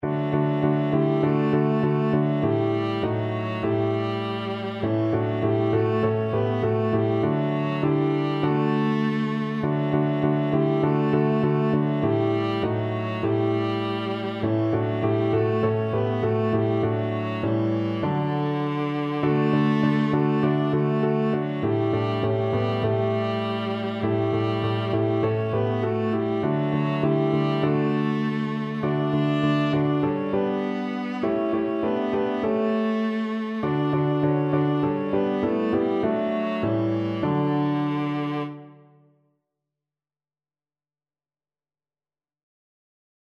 Classical (View more Classical Viola Music)